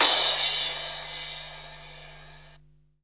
CRASHFX01 -R.wav